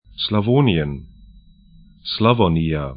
Pronunciation
Slawonien sla'vo:nĭən Slavonija 'slavɔ:nija sr Gebiet / region 45°05'N, 19°43'E